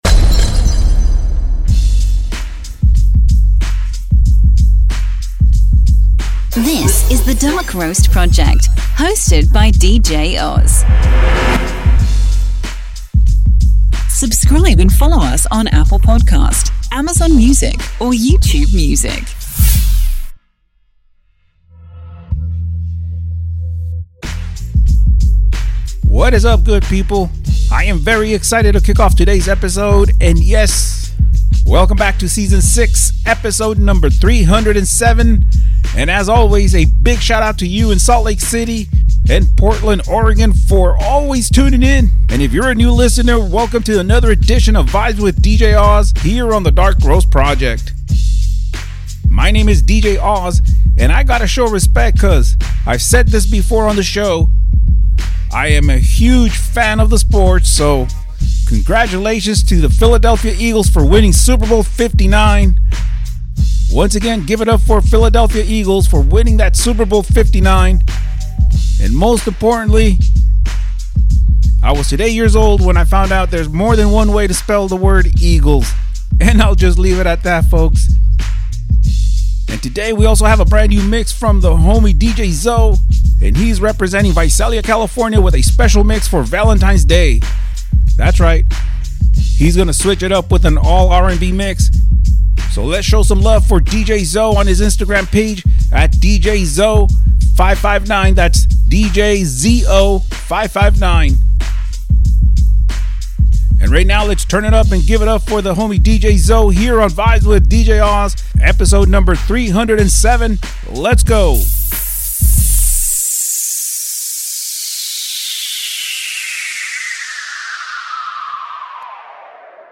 EXCLUSIVE VALENTINES DAY RNB THROWBACK MIX